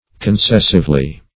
concessively - definition of concessively - synonyms, pronunciation, spelling from Free Dictionary Search Result for " concessively" : The Collaborative International Dictionary of English v.0.48: Concessively \Con*ces"sive*ly\, adv.